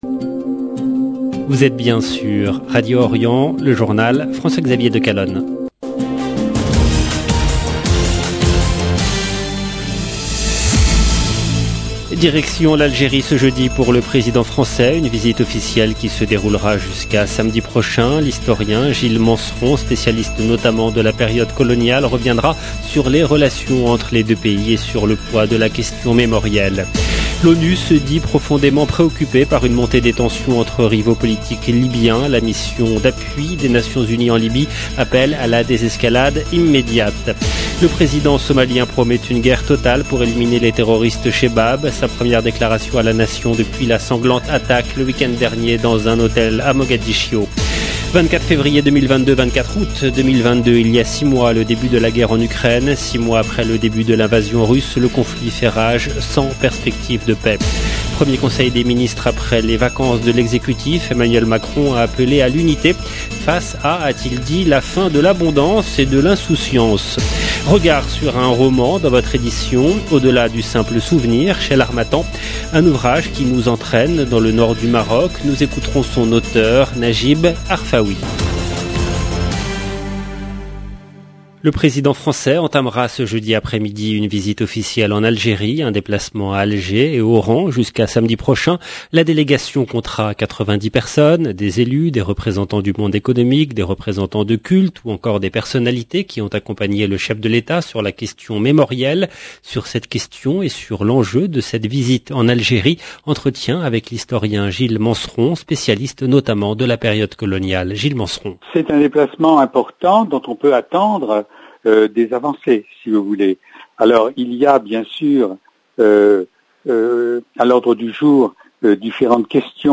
EDITION DU JOURNAL DU SOIR EN LANGUE FRANCAISE DU 24/8/2022